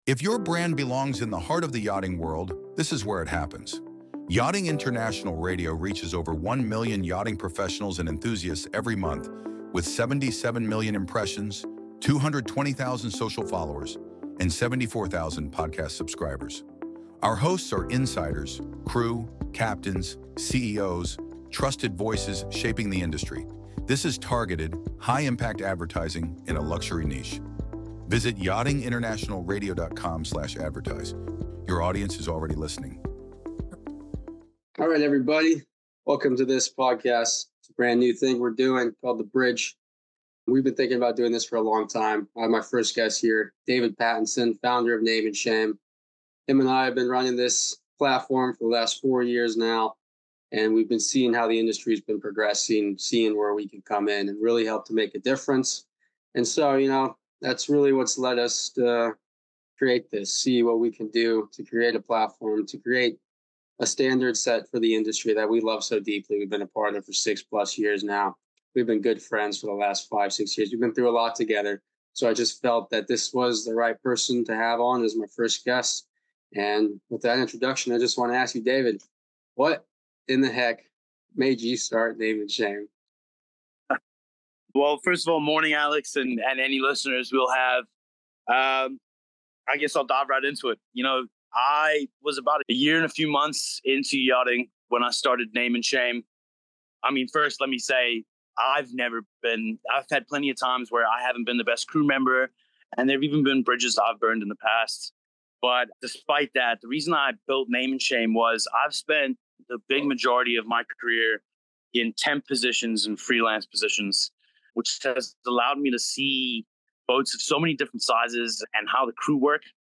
🚨 This is the conversation shaking up yachting.